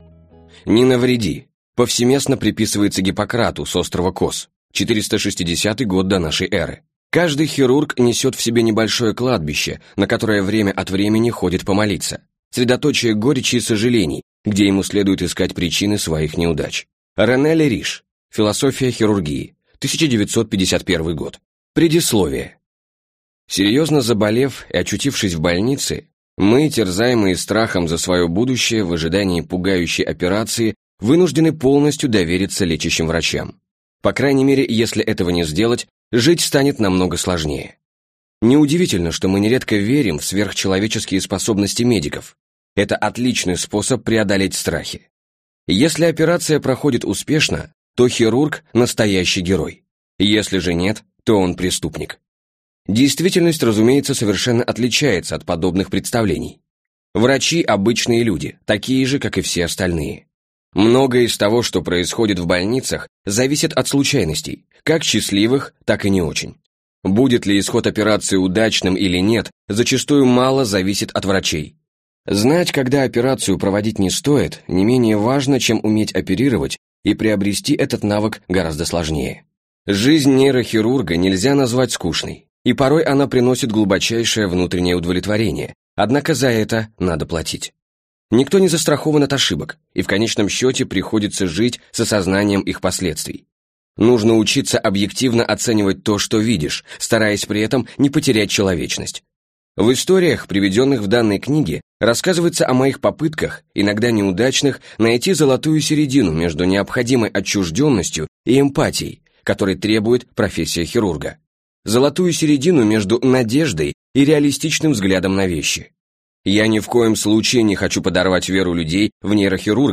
Аудиокнига Не навреди.